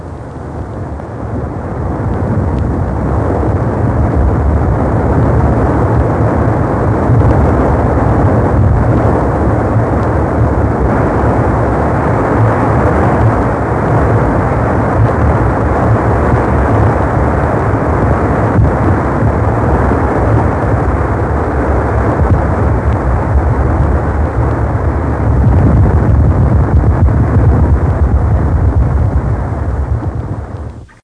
Pioggia ( ricezione sonar del rumore generato dalla ..) file wav
pioggia.wav